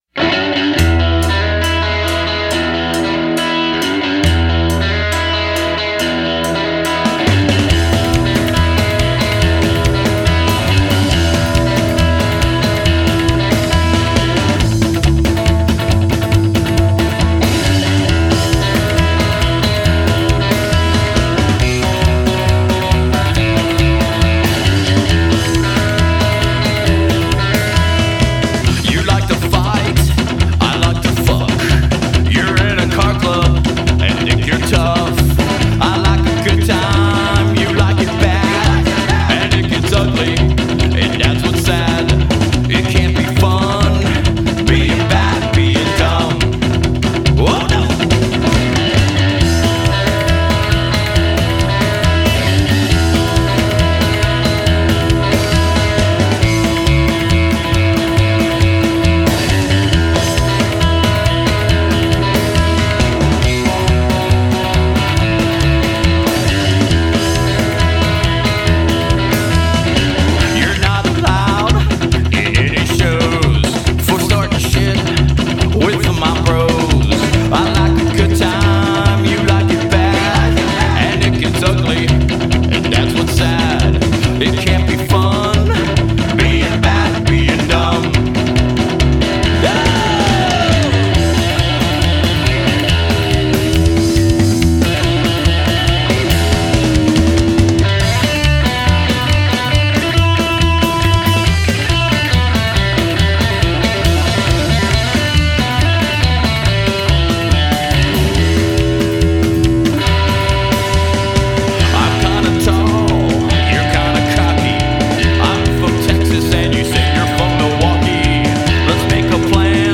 original revved up tunes